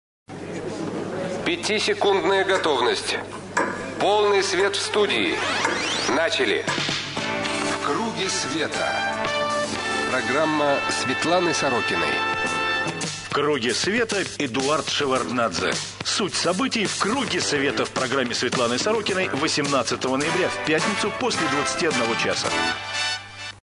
программа Светланы Сорокиной на радио «Эхо Москвы»
18 ноября 2005 г. Гость - Э.А.Шеварднадзе
Анонс: